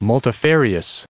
1702_multifarious.ogg